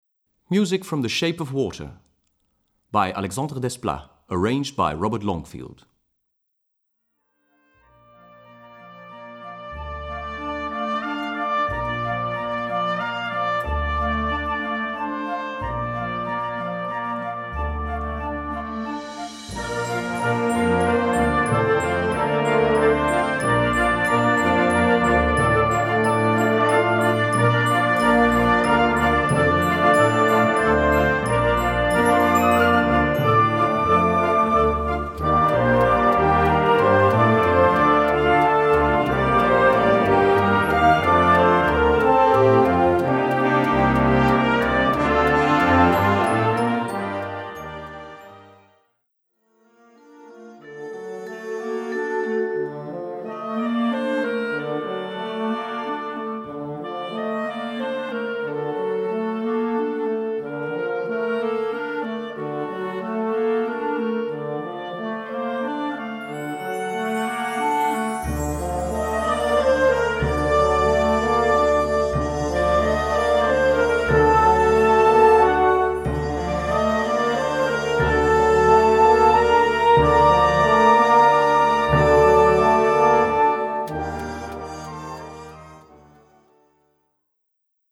Gattung: Filmmusik
Besetzung: Blasorchester